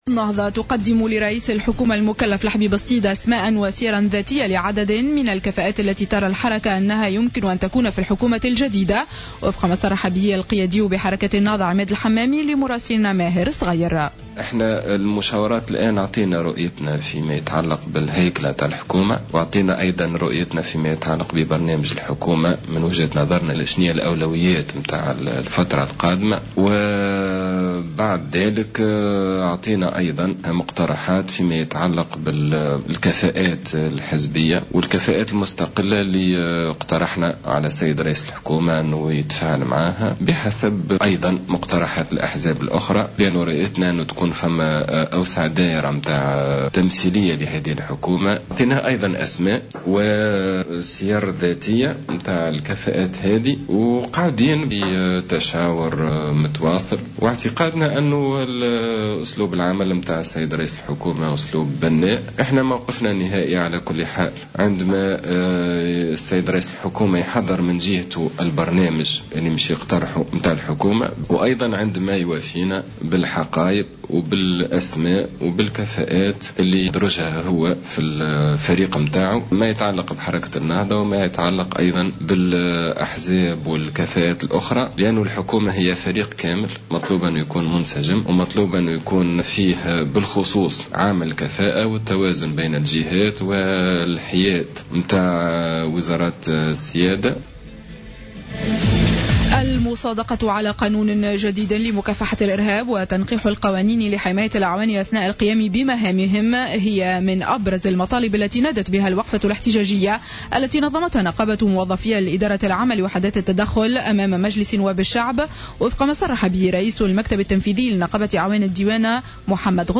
نشرة أخبار منتصف النهار ليوم الاثنين 19-01-15